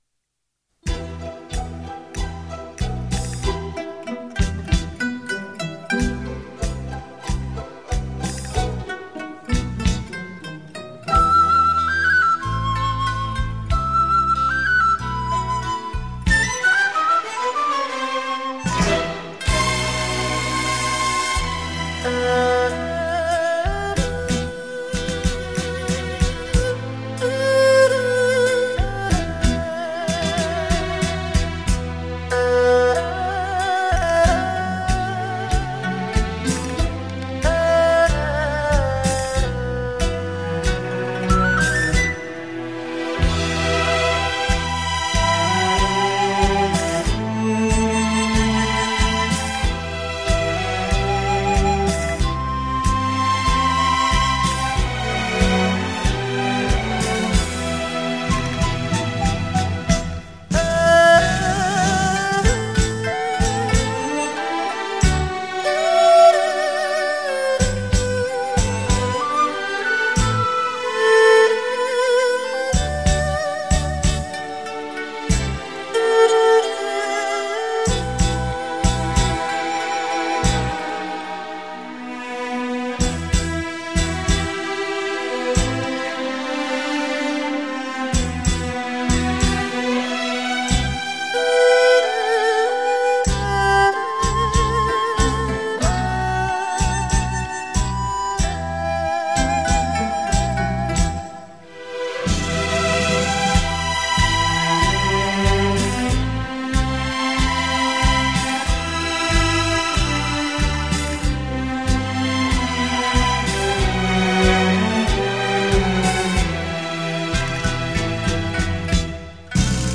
身历其境的临场效果
享受音乐的洗礼身历其境的临场音效